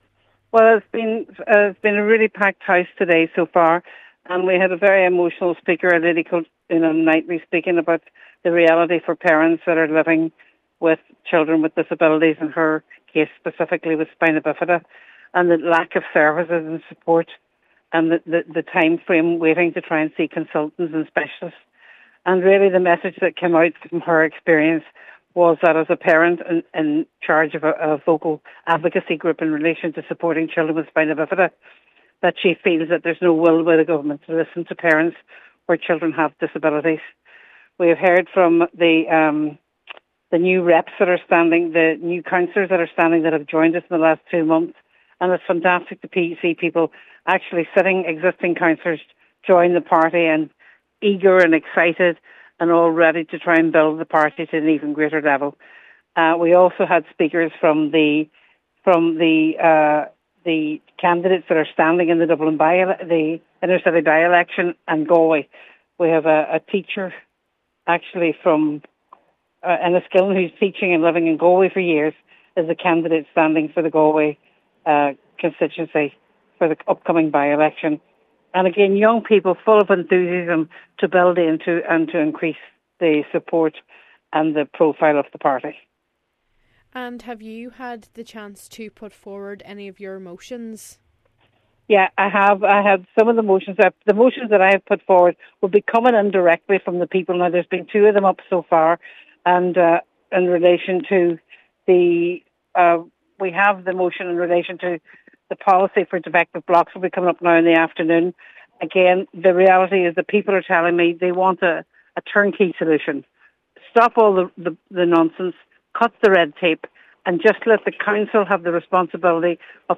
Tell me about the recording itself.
Donegal delegates have travelled to Aontu’s Ard Fheis in County Laois this afternoon.